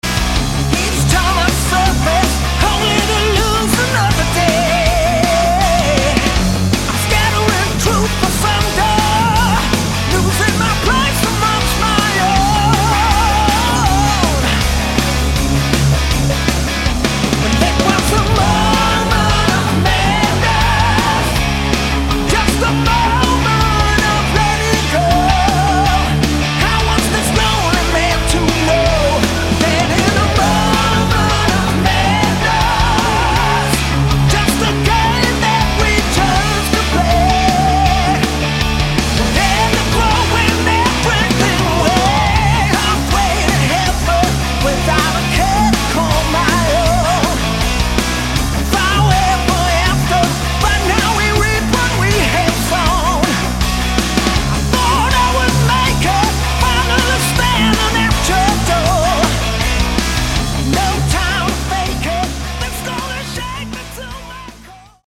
Lead vocals and backing vocals
Lead Guitars, Rhythm Guitars and Keyboards
Bass Guitars
Drums
we have huge choruses
melodic rock